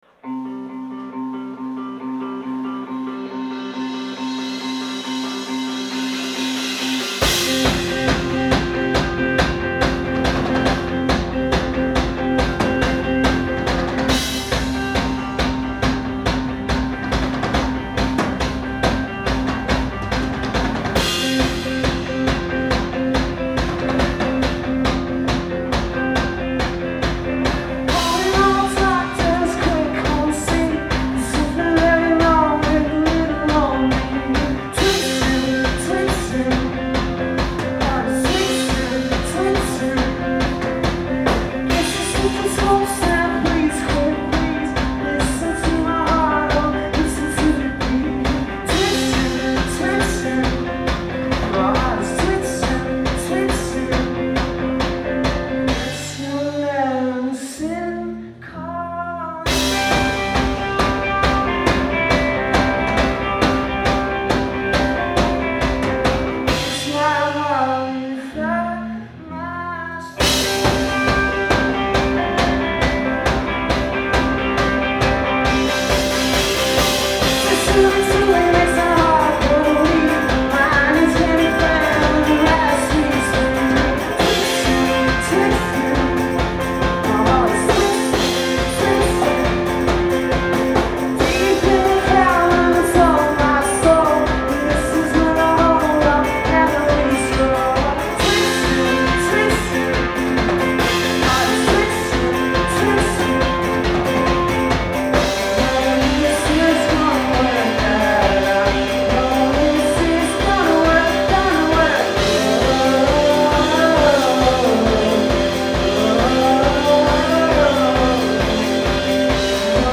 Live at the Middle East Downstairs